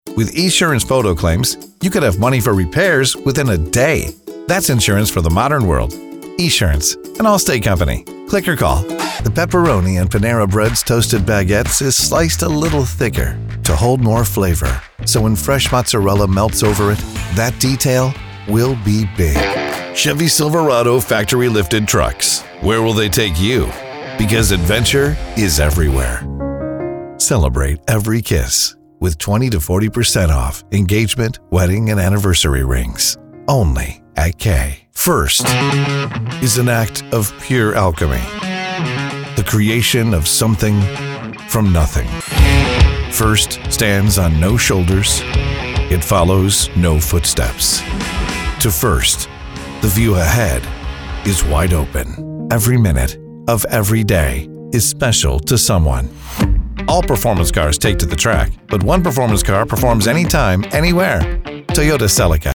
Male
English (North American), English (Neutral - Mid Trans Atlantic)
Adult (30-50), Older Sound (50+)
Announcer, conversational, non-announcer, guy next door, energy, high energy, Believable, Familiar, Natural, Upbeat, Real Person, Comforting, Friendly, Smooth, Sports, Persuasive, Classy, Authoritative, Big, Barney the Dinosaur, Young, approachable, fast, sincere, dynamic, trustworthy, hollywood
Main Demo
Male Voice Over Talent